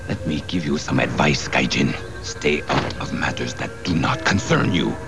From X-Men: The Animated Series.
samuri_sound_2.wav